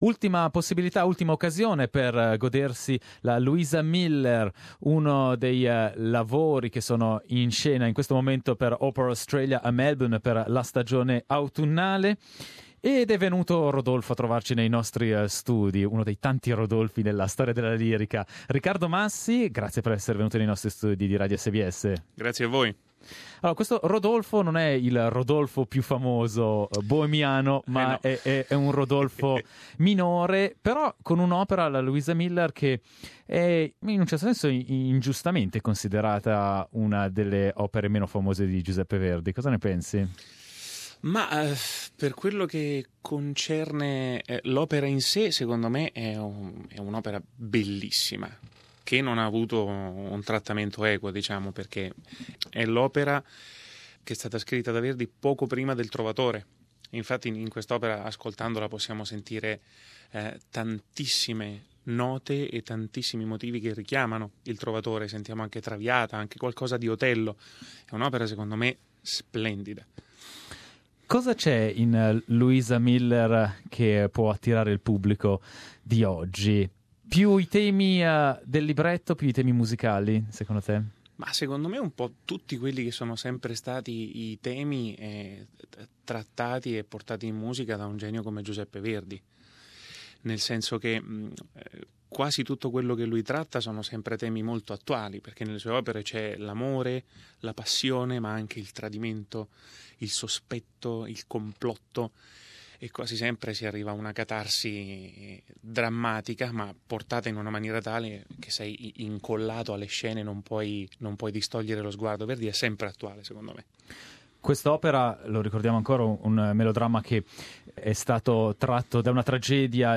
Il tenore Riccardo Massi ha trascorso un intensa attività di lavoro in Australia esibendosi prima nel ruolo di Calaf nella Turandot di Giacomo Puccini a Sydney, e subito dopo in quello di Rodolfo nella Luisa Miller di Giuseppe Verdi. In questa intervista Riccardo Massi parla di questa entusiasmante esperienza australiana.